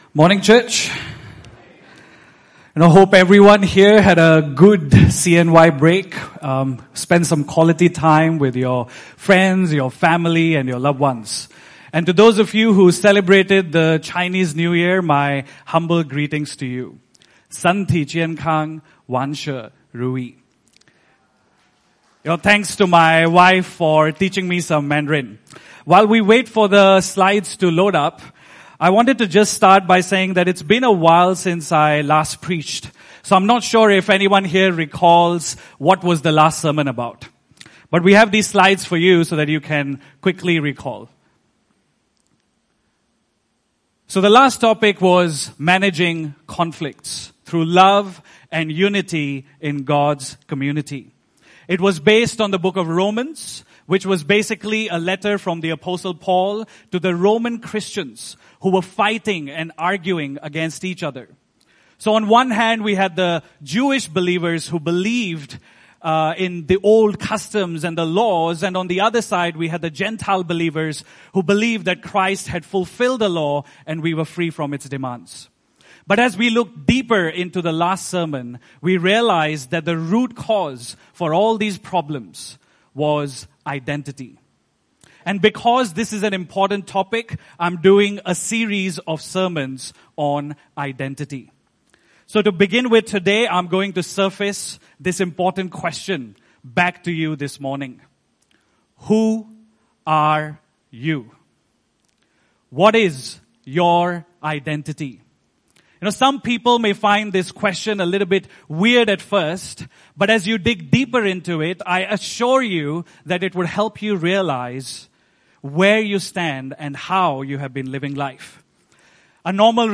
Service Type: Sunday Service (Desa ParkCity)